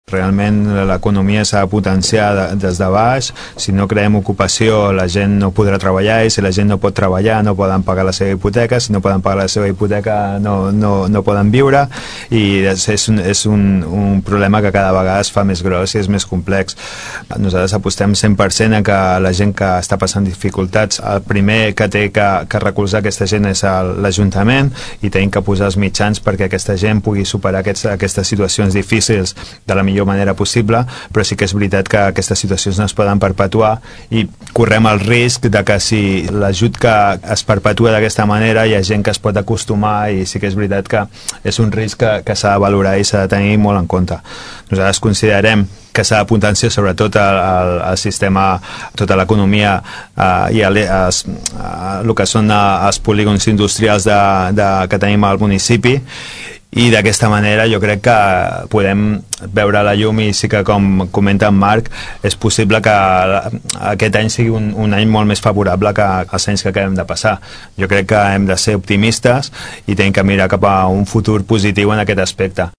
La tertúlia de polítics se celebra cada mes, la següent setmana després del plenari municipal.